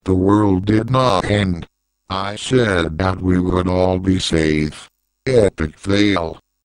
Choose microsoft sam as the voice and here you go, hawkings is here!